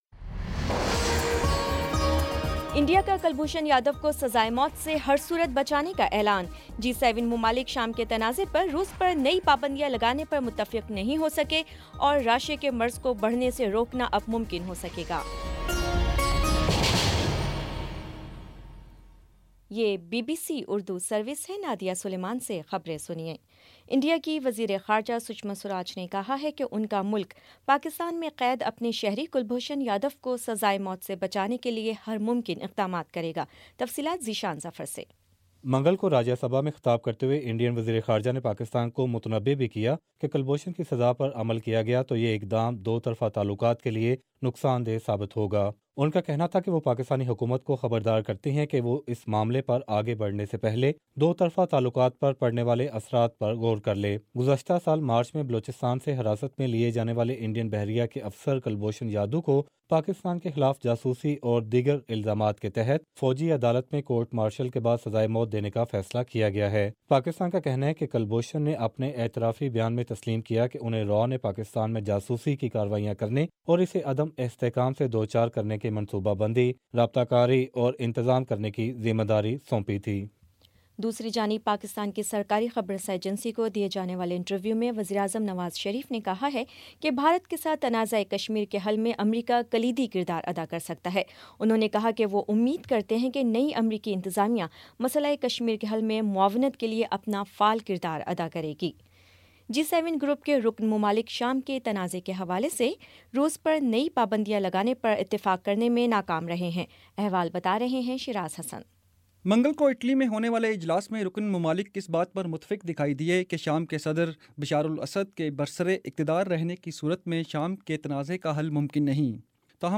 اپریل 11 : شام چھ بجے کا نیوز بُلیٹن